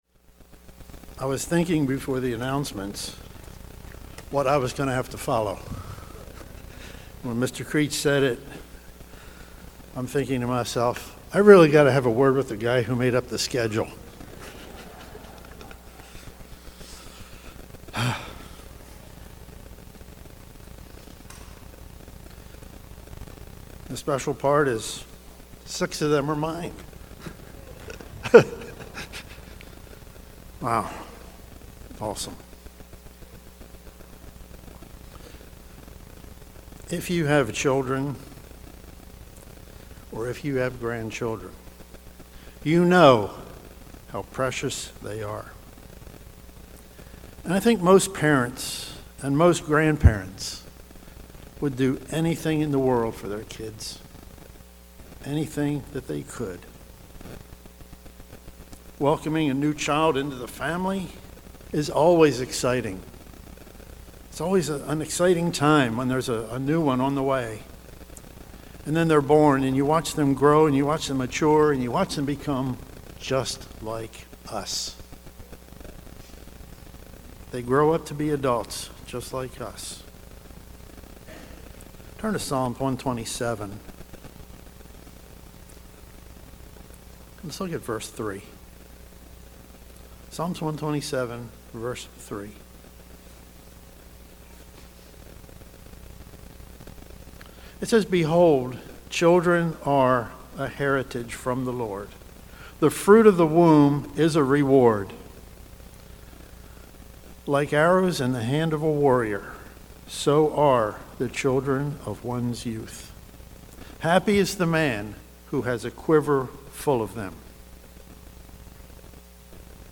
This sermon was given at the Ocean City, Maryland 2022 Feast site.